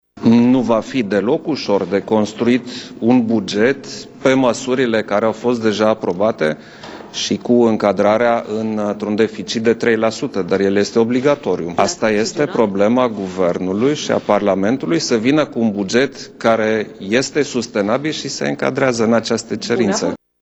Iohannis susține că nu va fi deloc ușor de construit un buget cu măsurile deja aprobate și cu încadrarea în deficitul de 3%: